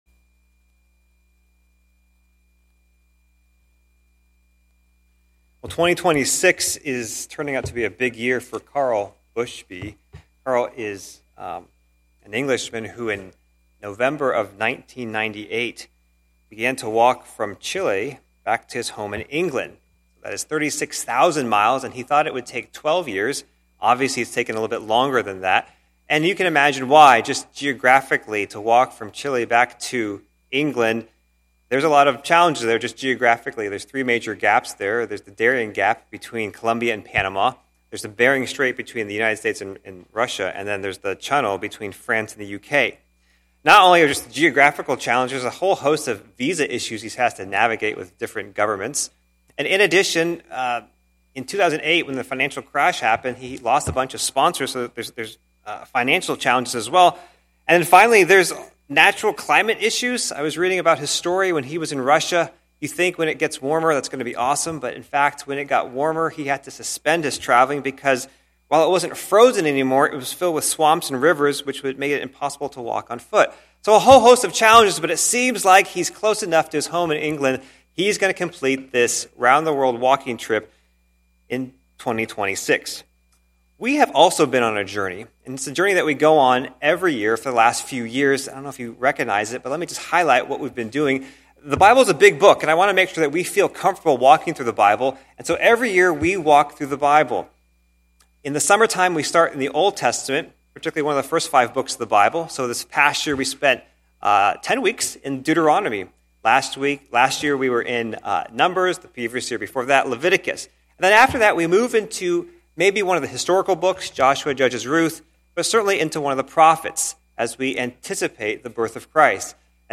Sermons by CCCI